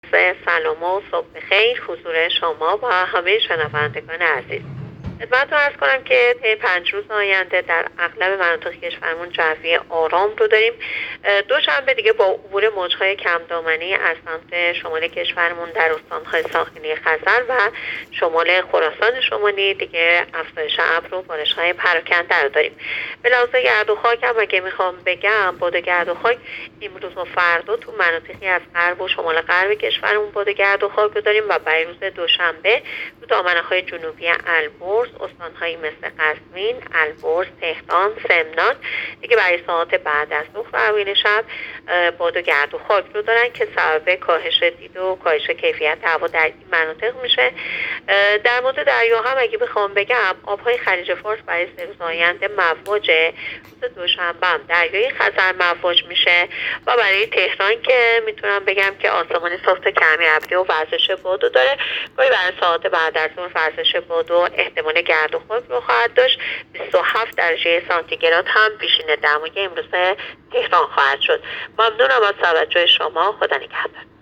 گزارش رادیو اینترنتی پایگاه‌ خبری از آخرین وضعیت آب‌وهوای سوم آبان؛